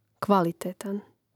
kvȁlitētan kvalitetan